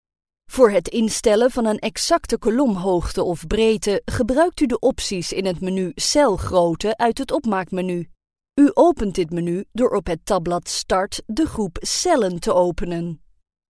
NL CR EL 01 eLearning/Training Female Dutch